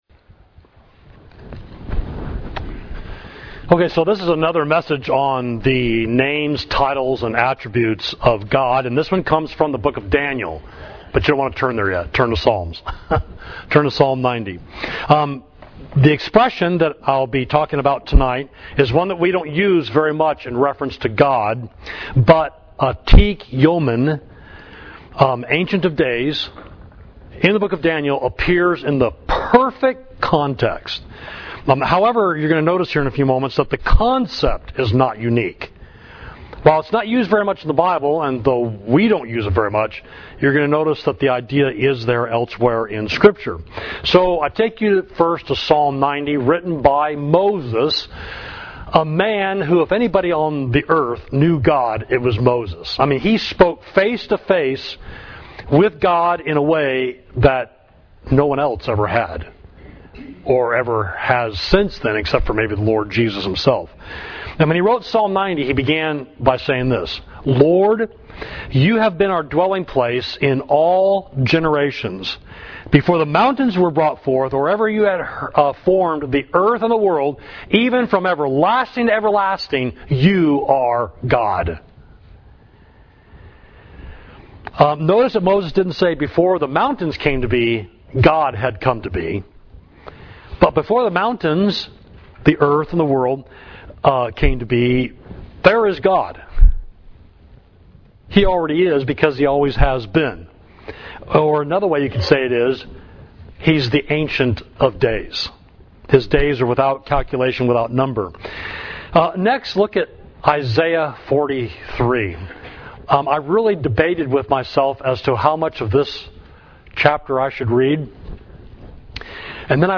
Sermon: Atik Yomin – Ancient of Days, Daniel 7.13–14